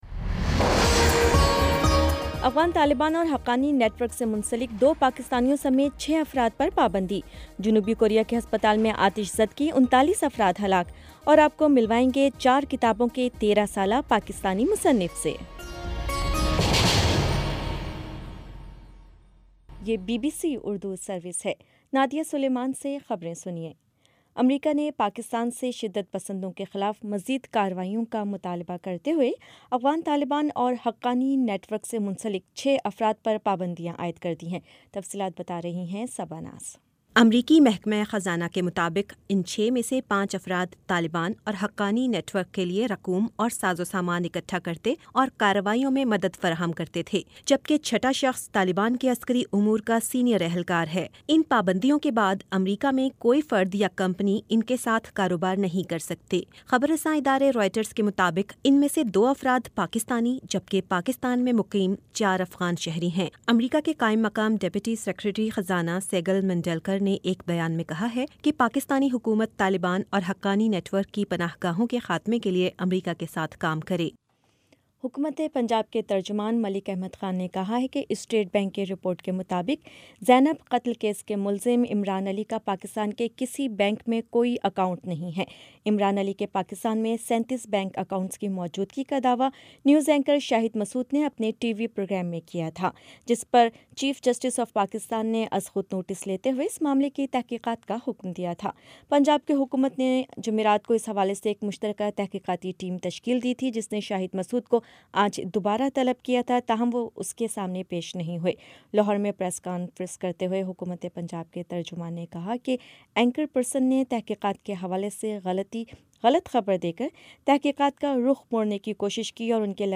جنوری 26 : شام پانچ بجے کا نیوز بُلیٹن
دس منٹ کا نیوز بُلیٹن روزانہ پاکستانی وقت کے مطابق شام 5 بجے، 6 بجے اور پھر 7 بجے۔